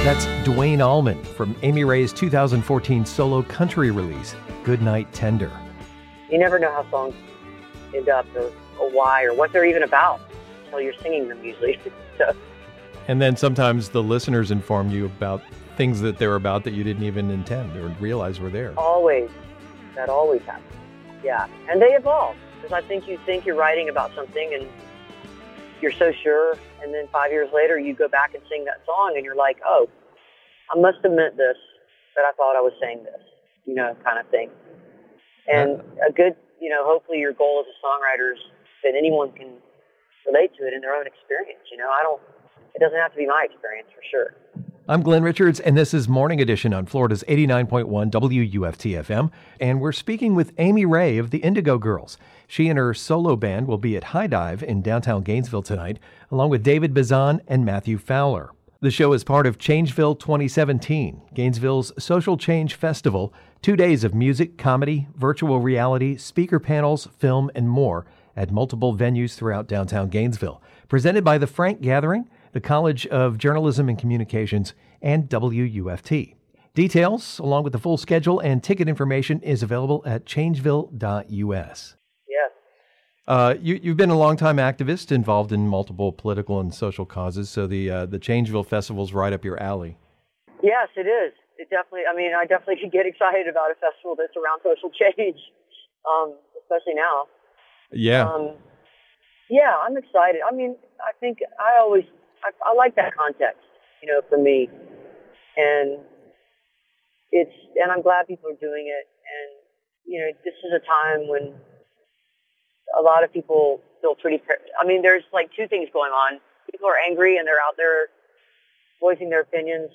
(recorded from webcast)
05. interview (3:12)